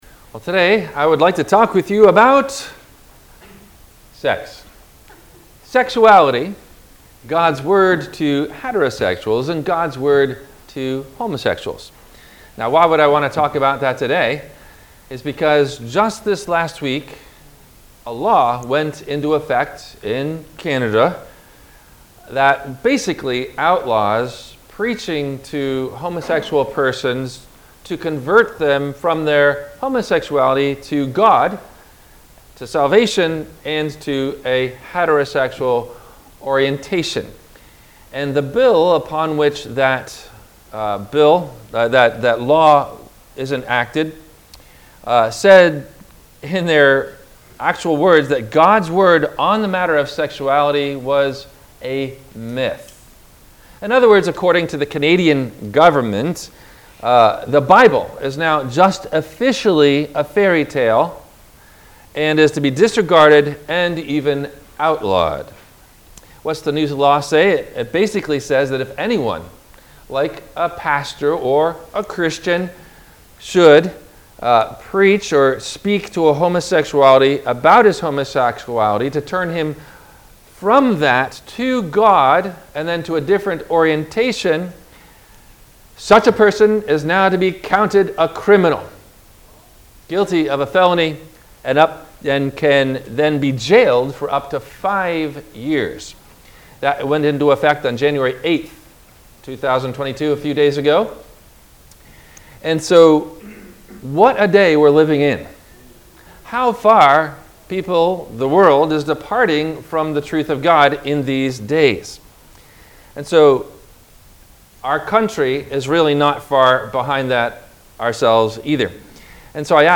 God's Design For Sexuality – WMIE Radio Sermon – January 24 2022 - Christ Lutheran Cape Canaveral